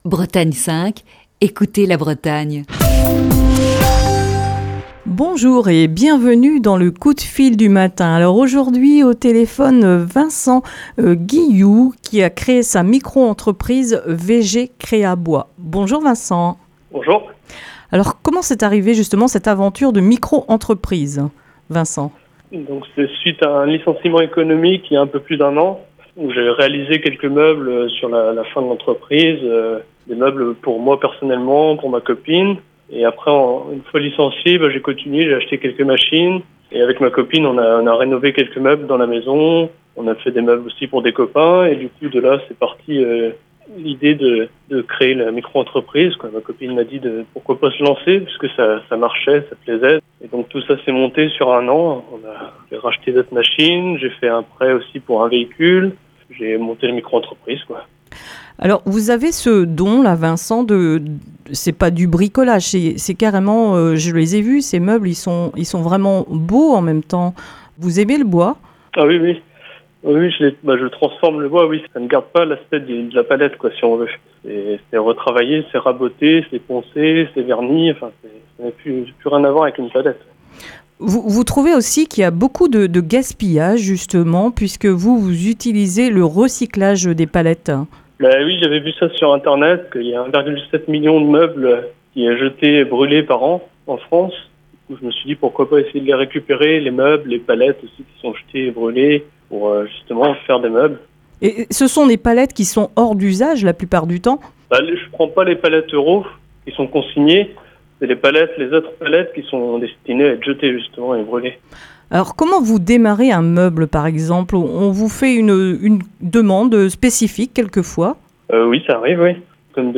Dans le coup de fil du matin de ce jeudi